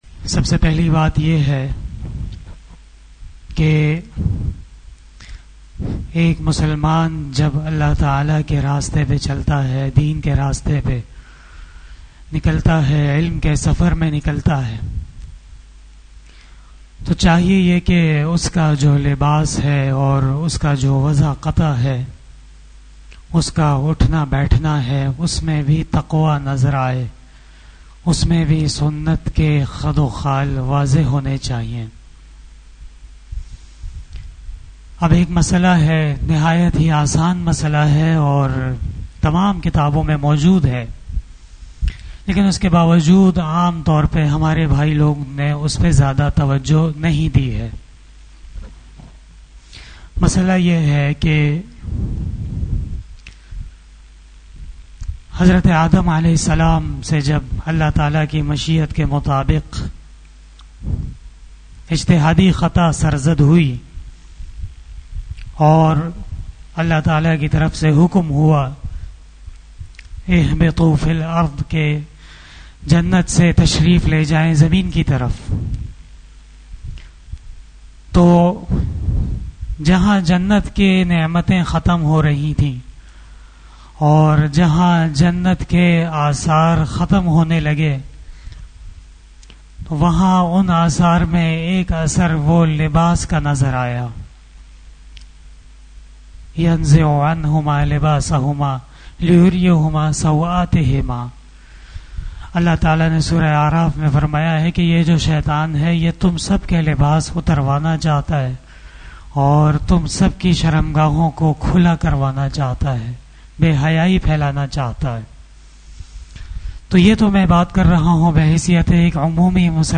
After magrib Namaz Bayan